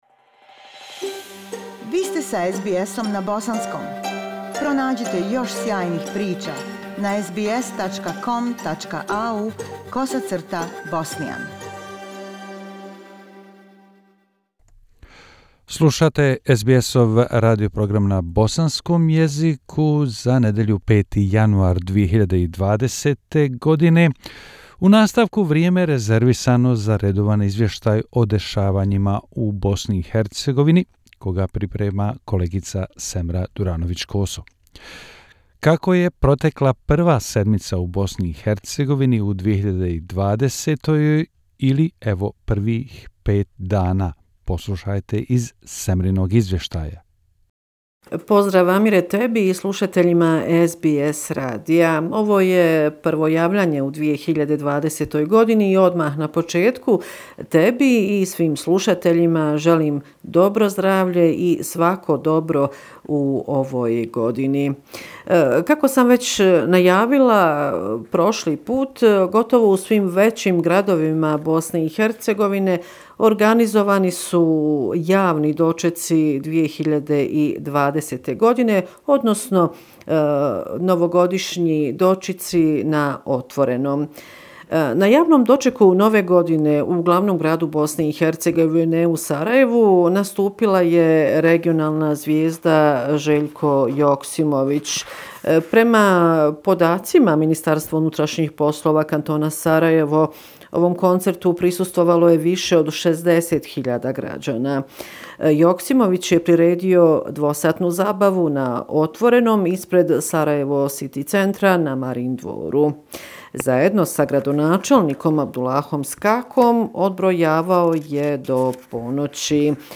Bosnia and Herzegovina - affairs in the country for the last seven day, weekly report January 5, 202O